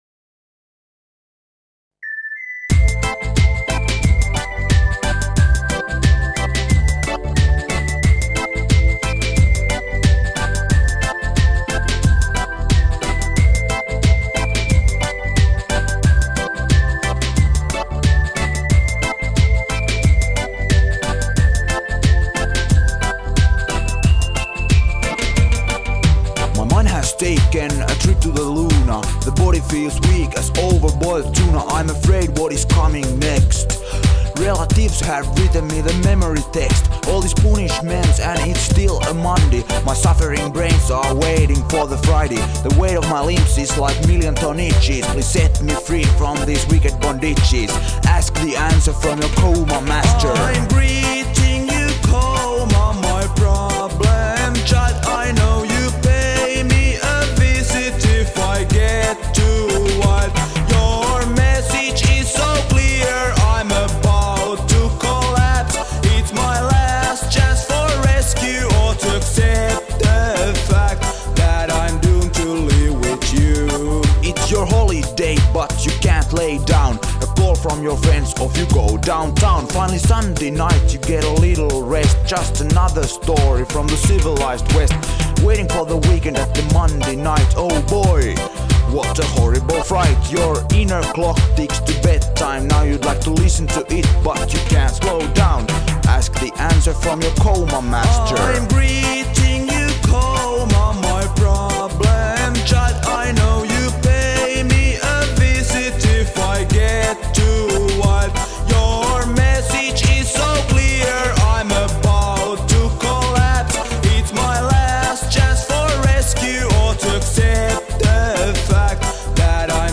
Roland JP-8000, Korg 01/W, ja Shure SM58s.